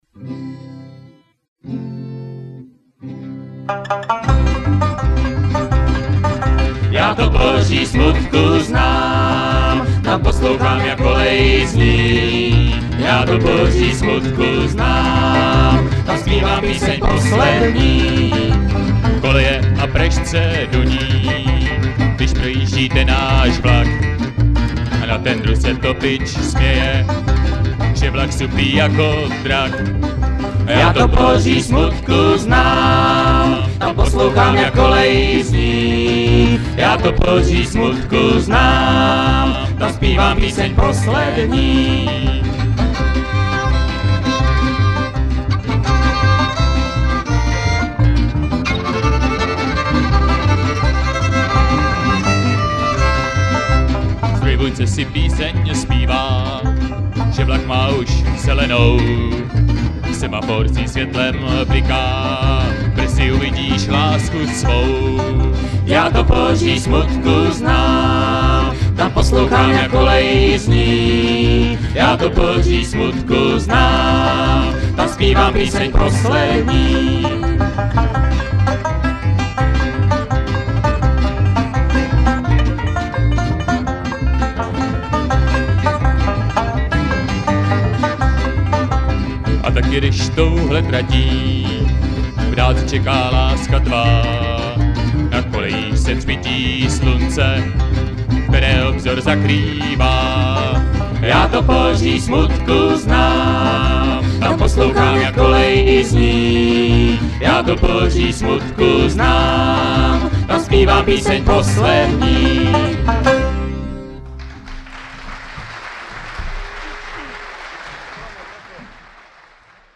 Nahrávka pochází z kotoučáku pana zvukaře.